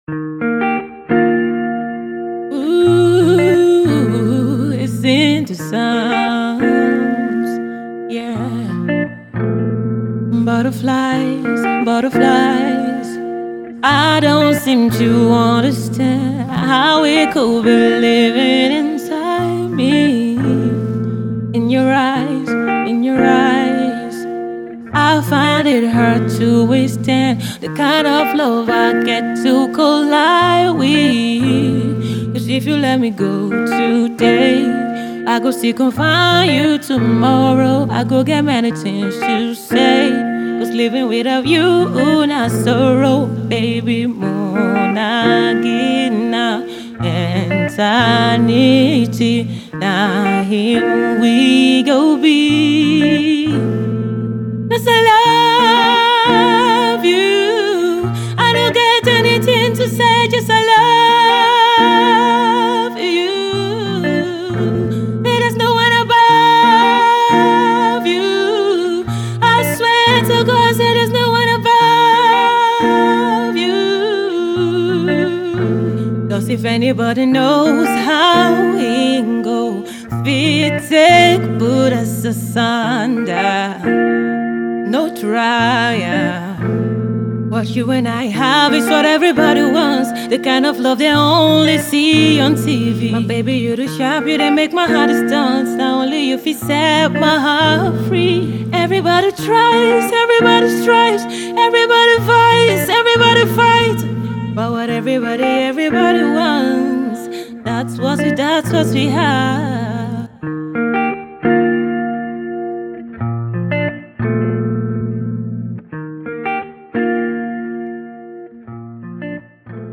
female rapper and singer